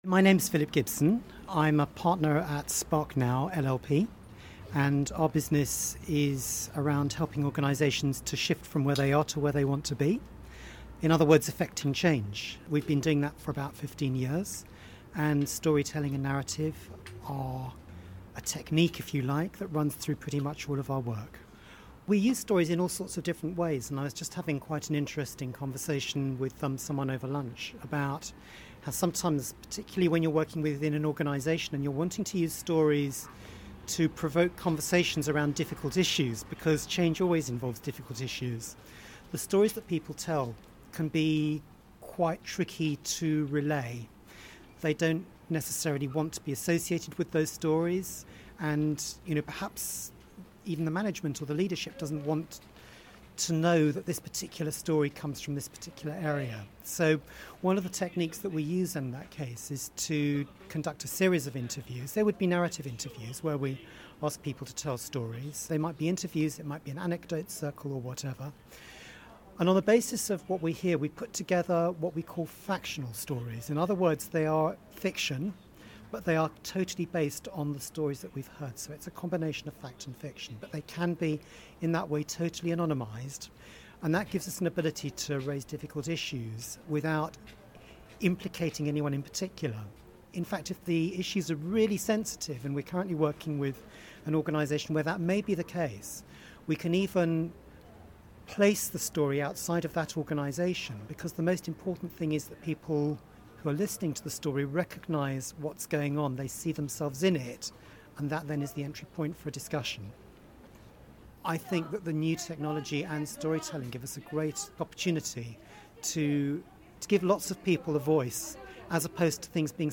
at the Digital Storytelling conference in Cardiff